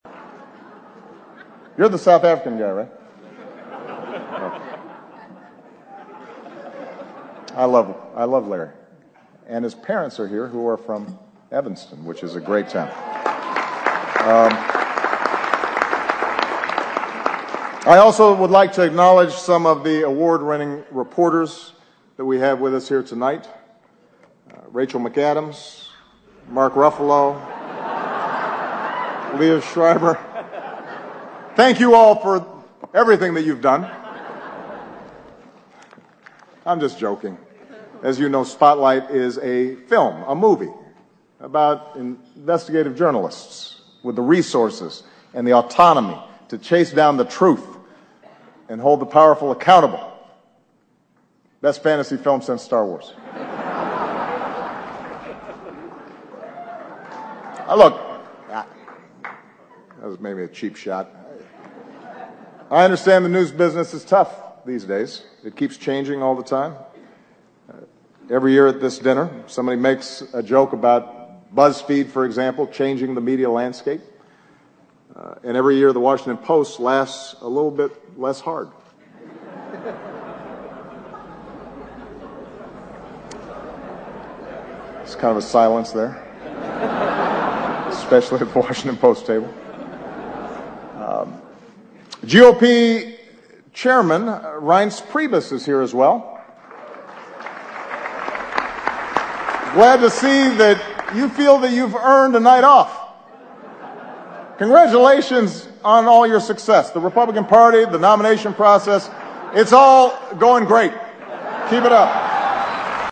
欧美名人演讲 第84期:奥巴马任内末次白宫记者晚宴演讲(6) 听力文件下载—在线英语听力室